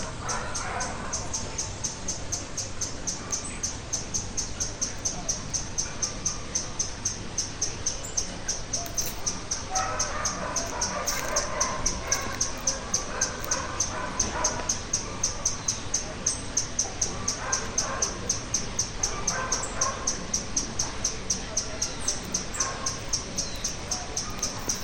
White-throated Hummingbird (Leucochloris albicollis)
Location or protected area: Gran Buenos Aires Norte
Condition: Wild
Certainty: Observed, Recorded vocal